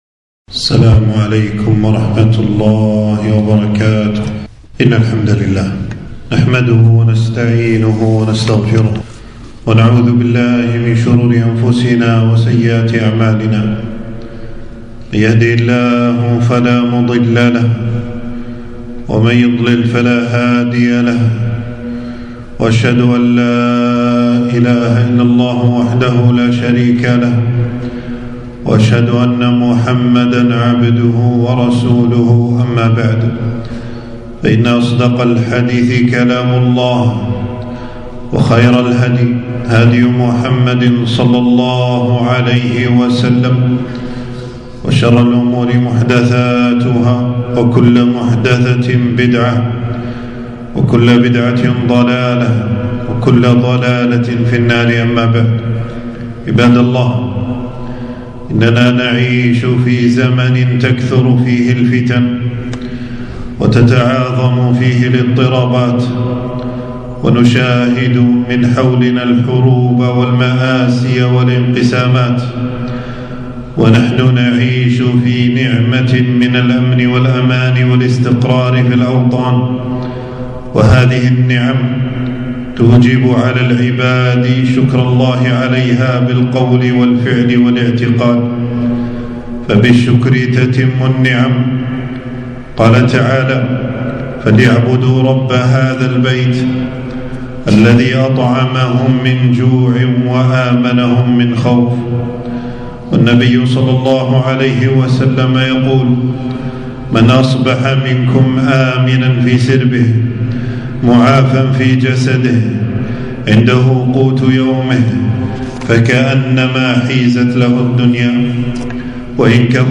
خطبة - وصايا زمن الفتن